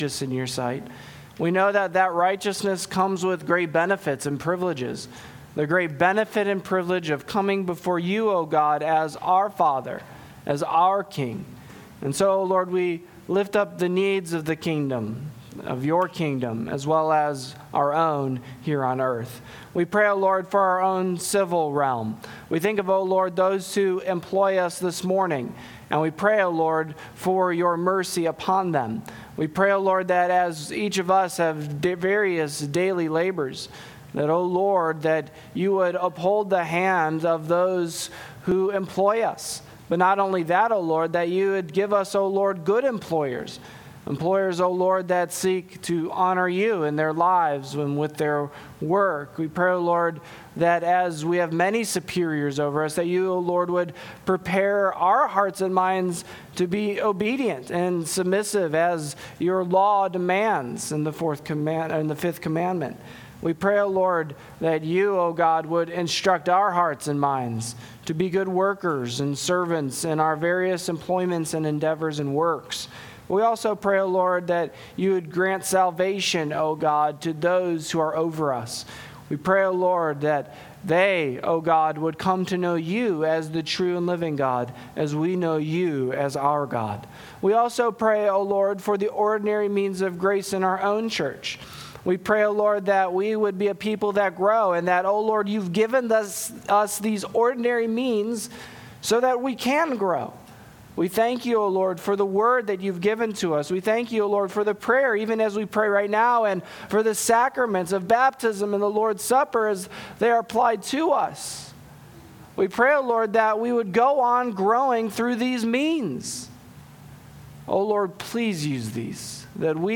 Sermons Podcast - Counted Righteous Part 1 | Free Listening on Podbean App